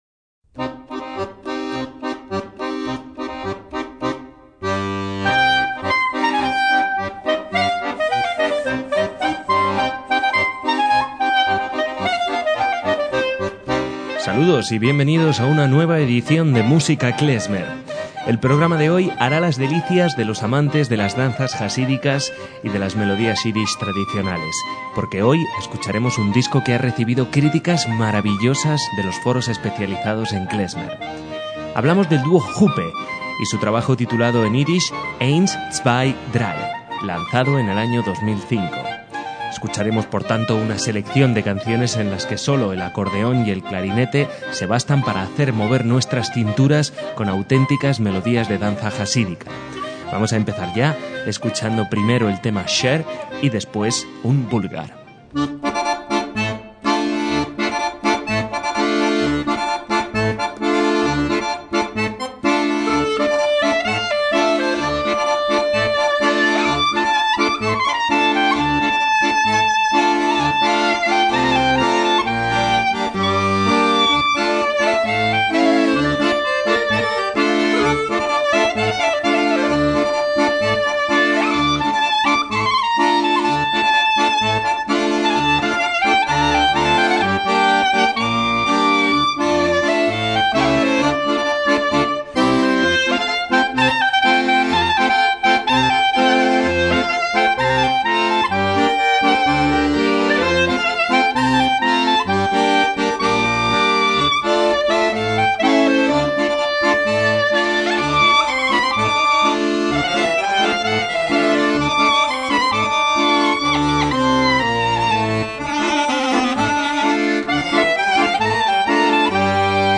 MÚSICA KLEZMER - El duo alemán de klezmer
clarinetista
acordeonista